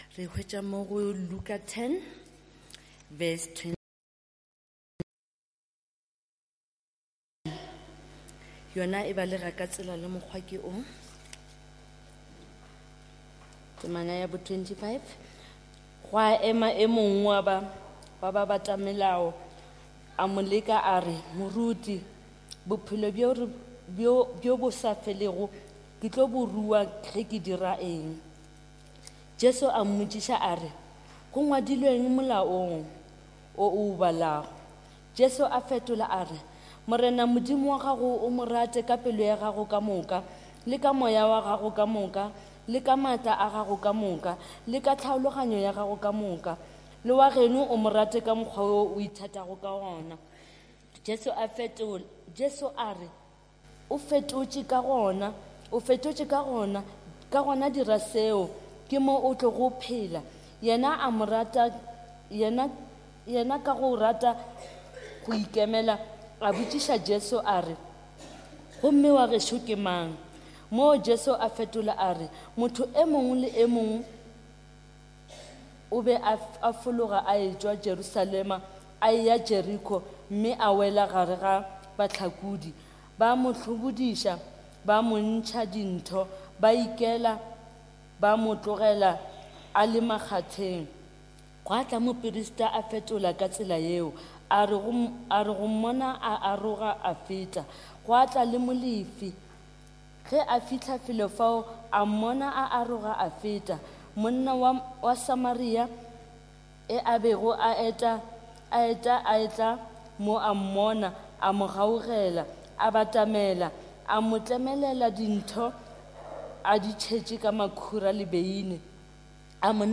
Trinity Methodist Church Sermons